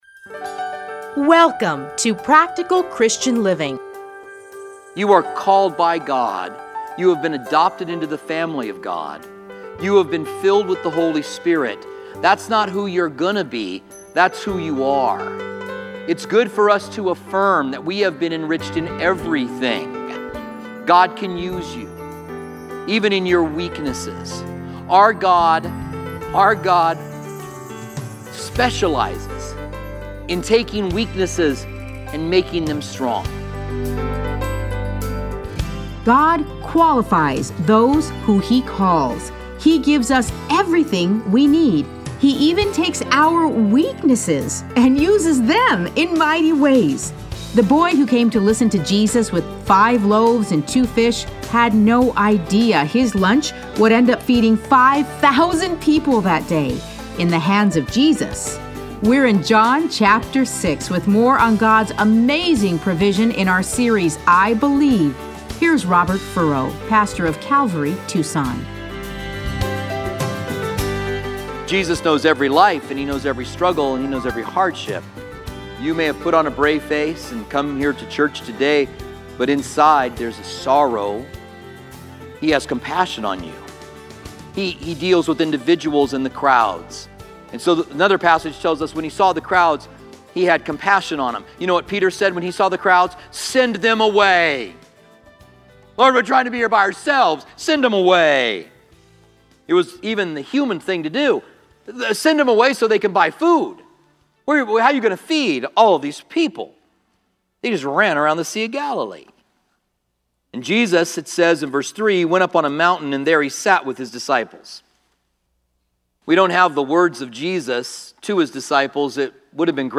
Listen to a teaching from John 6:1-14.